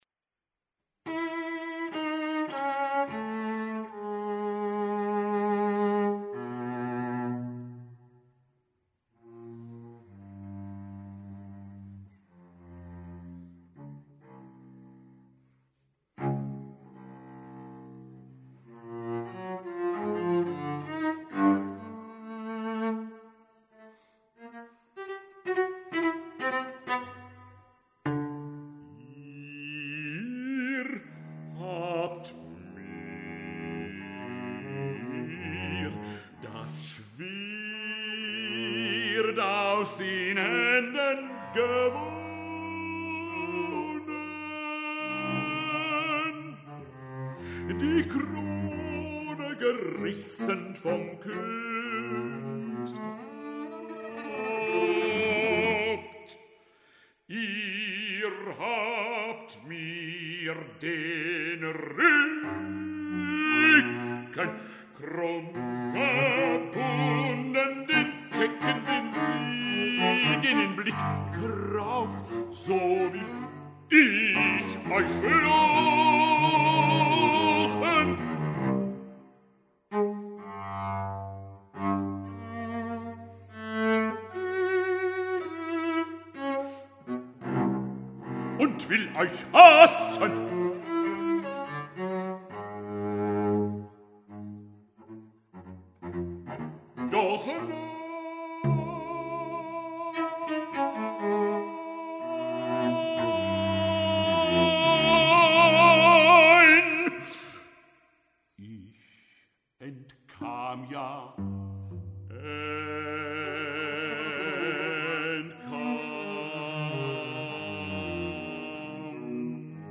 • Bezetting: bariton, violoncello
violoncello
van een concert op kasteel Ehrenstein te Kerkrade